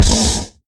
Minecraft Version Minecraft Version snapshot Latest Release | Latest Snapshot snapshot / assets / minecraft / sounds / mob / horse / skeleton / hit4.ogg Compare With Compare With Latest Release | Latest Snapshot